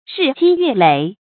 注音：ㄖㄧˋ ㄐㄧ ㄩㄝˋ ㄌㄟˇ
日積月累的讀法